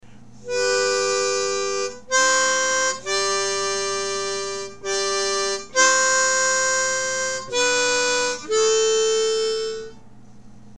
We’re using a G major diatonic and playing in 1st position.